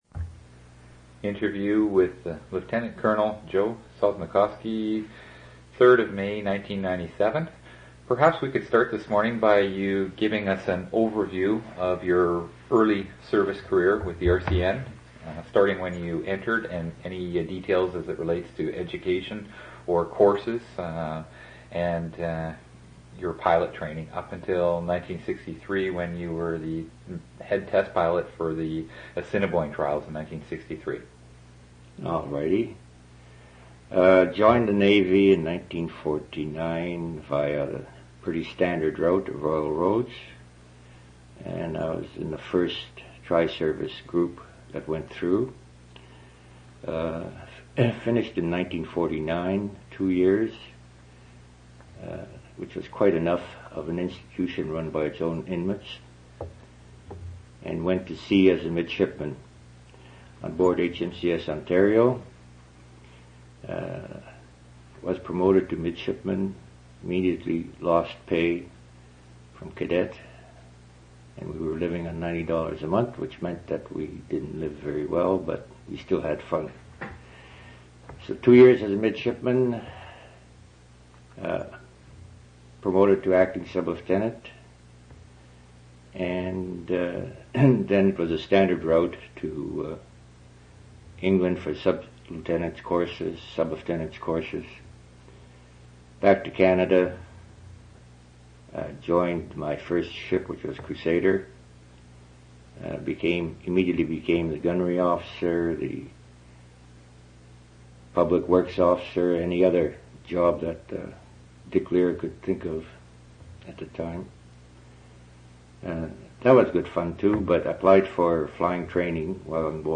Two original audio cassettes in Special Collections.
oral histories (literary genre) reminiscences interviews